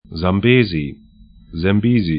Sambesi zam'be:zi